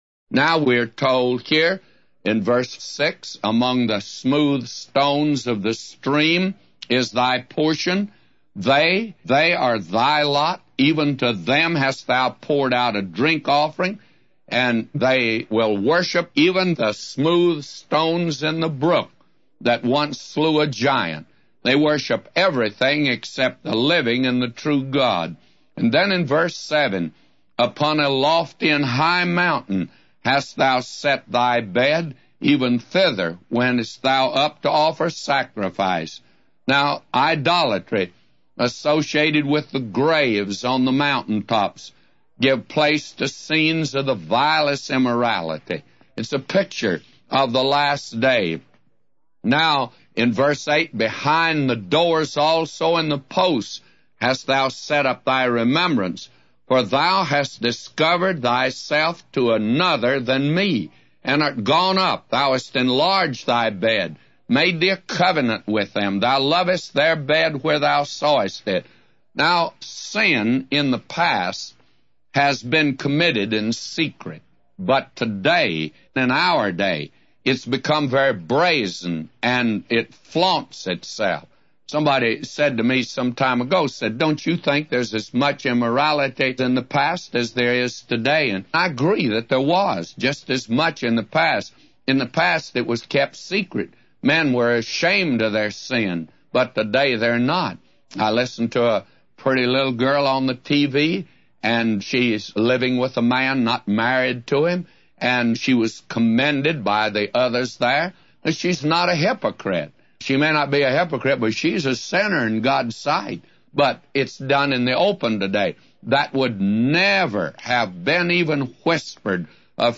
A Commentary By J Vernon MCgee For Isaiah 57:6-999